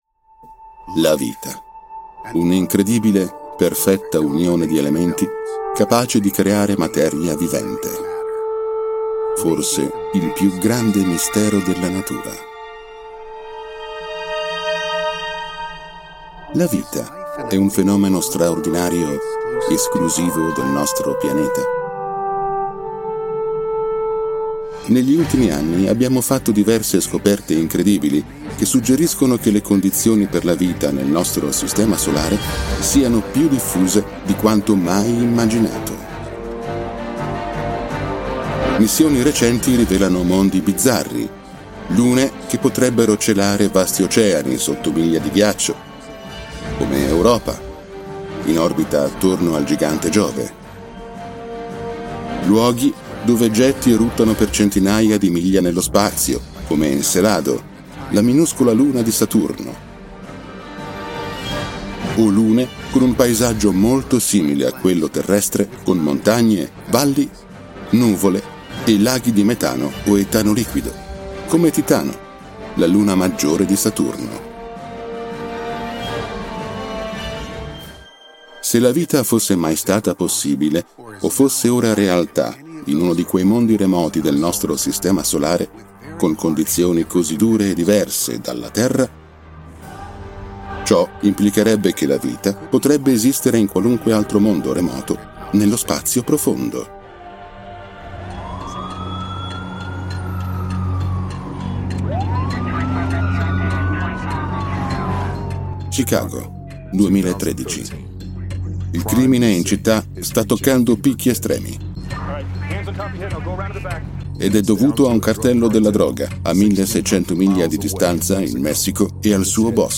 La mia voce è baritonale, calda e musicale: matura, chiara e naturale, con un tono accogliente ma deciso, ideale per progetti che richiedono credibilità e presenza.
Sprechprobe: Industrie (Muttersprache):